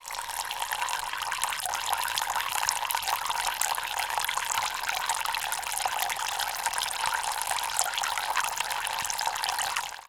bathroom-sink-02
bath bathroom bubble burp click drain dribble dripping sound effect free sound royalty free Sound Effects